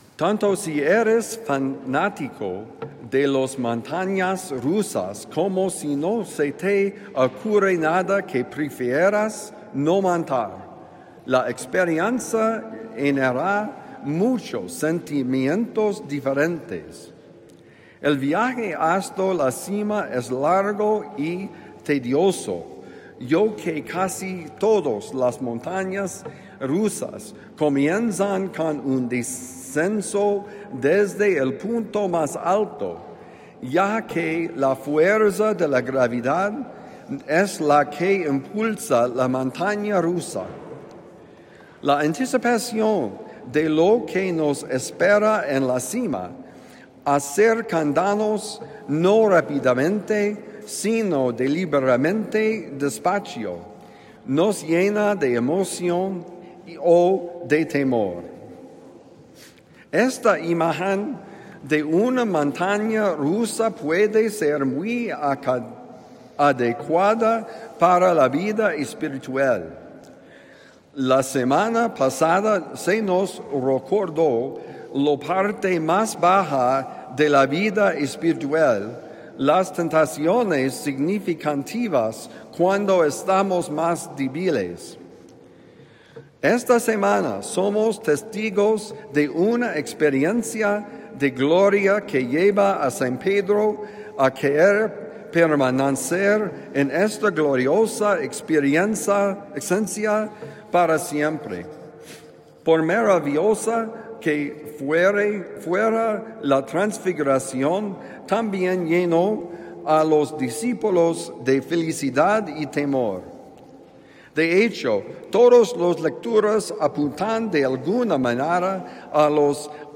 Montaña rusa: Homilía para el domingo 1 de marzo de 2026 – The Friar